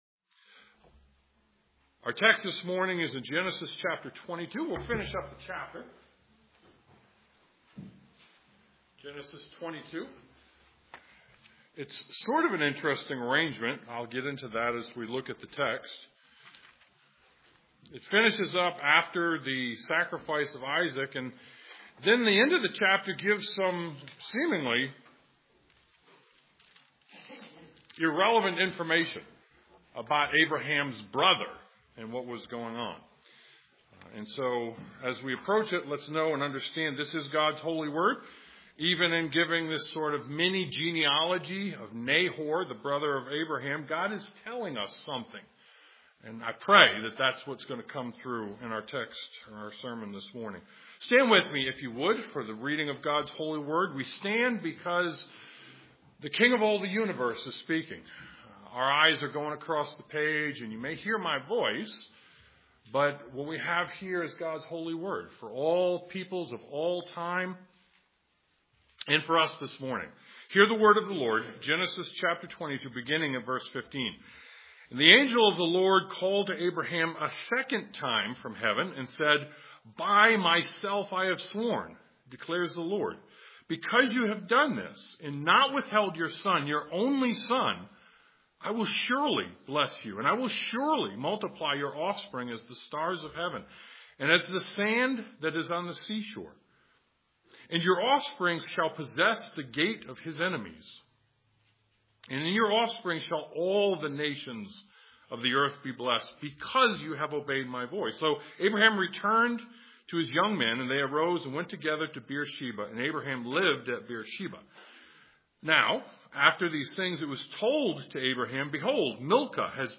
Genesis 22:15-24 Service Type: Sunday Morning Genesis 22:15-24 God’s promises of how and when He will bless us are better than the best this life can give us now.